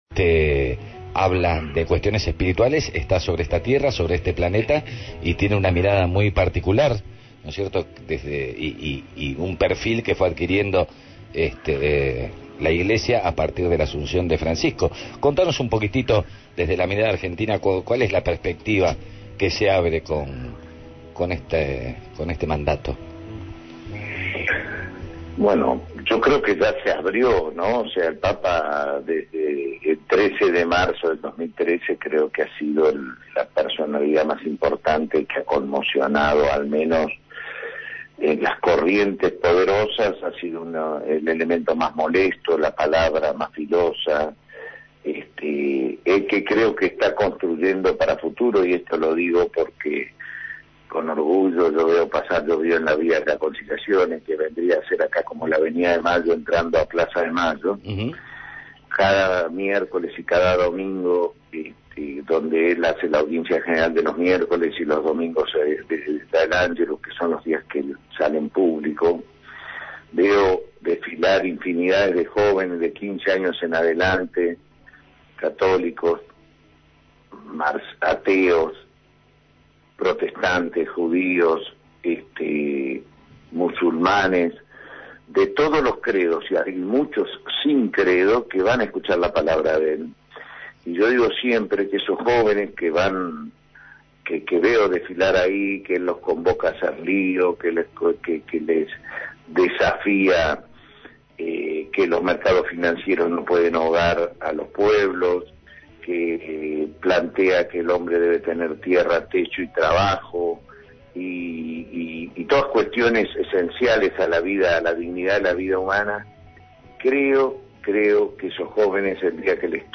Eduardo Valdés, embajador argentino ante la Santa Sede, fue entrevistado en Los Domingos no son Puro Cuento.